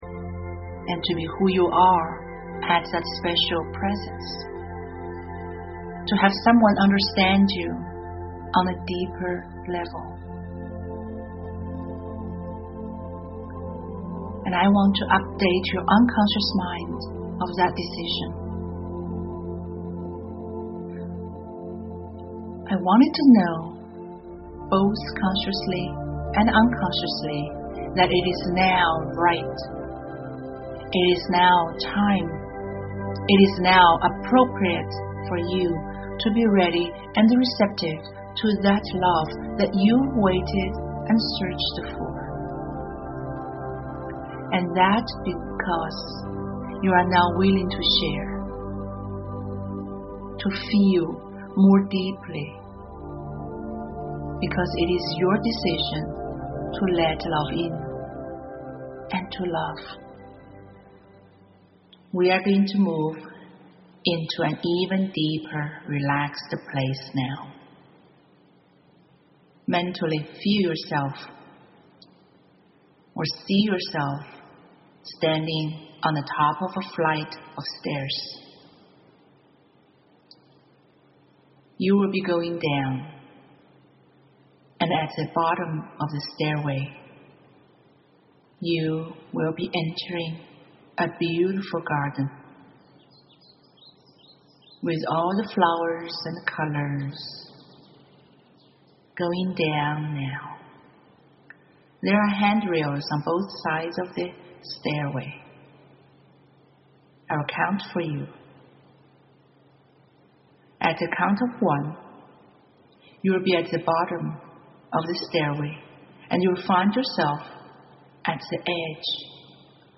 A high-quality production of a guided journey into the beautiful garden of your mind and meet him – your true love. This hypnosis home session strengthens your self-image, nurtures your mind and soul, and offers you a chance to look into the near future, where you get insights into everything about him.
Attract-Him-Your-True-Love-Excerpt-1013-.mp3